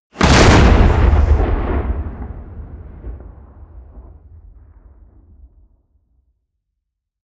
Gemafreie Sounds: Explosionen